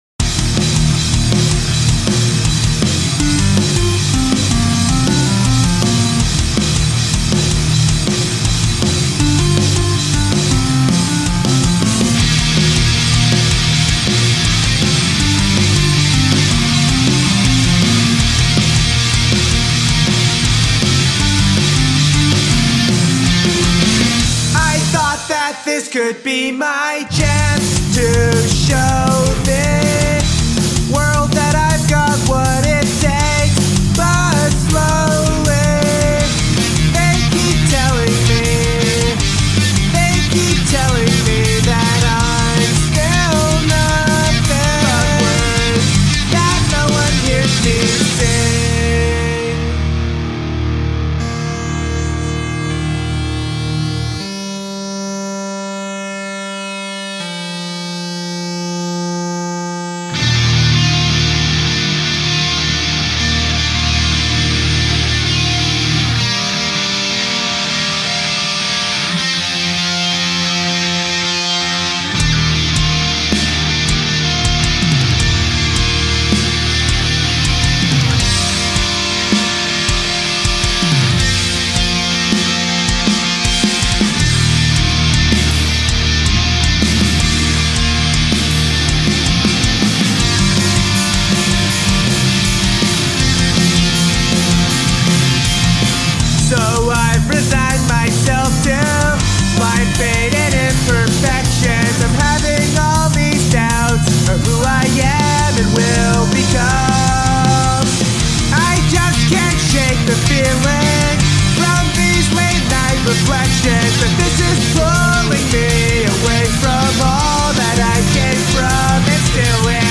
**PLEASE COMMENT, LIKE, AND/OR SUBSCRIBE!** NEW YEAR, TIEM FOR FLOOTERSHAI SONG Finally, after five months, a new ORIGINAL full band full-length by yours truly!
This is and probably will be the most experimental I've gotten with my music, but I hope you'll like this style as much as I do!
First, I changed my set-up for recording guitars, and I think they sound cleaner and less muddy. Second, I improved A LOT on mixing.